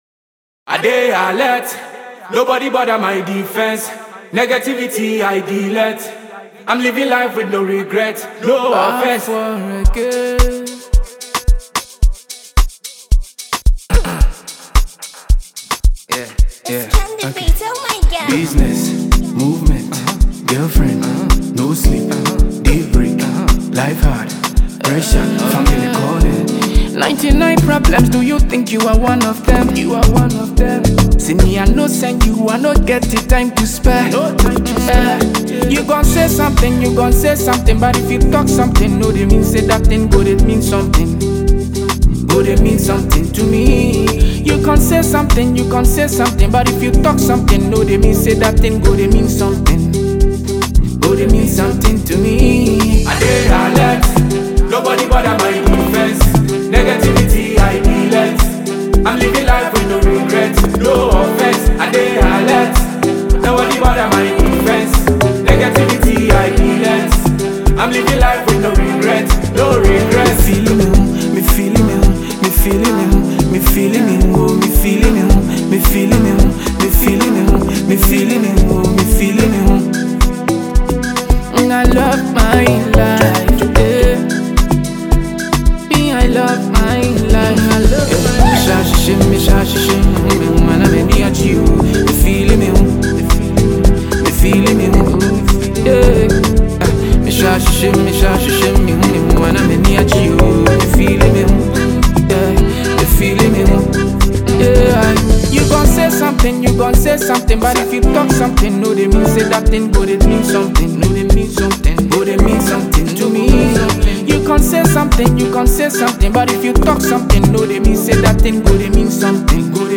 With its infectious beats and empowering lyrics